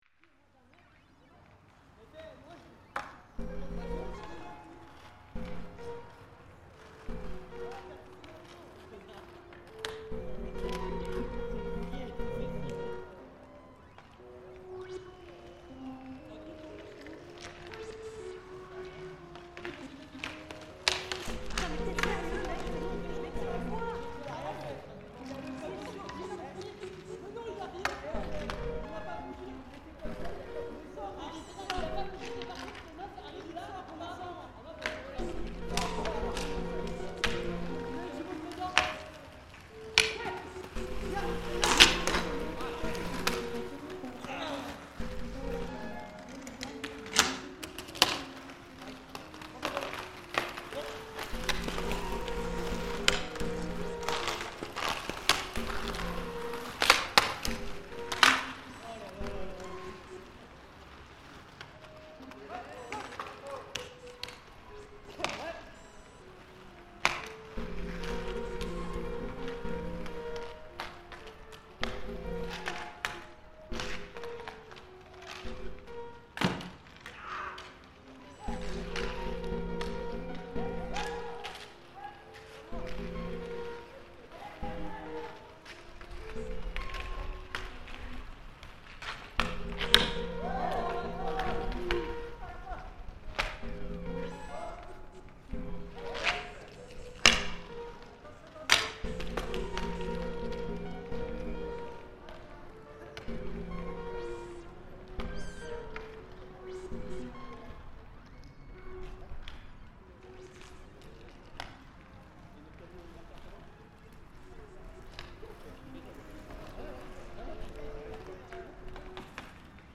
Paris street hockey reimagined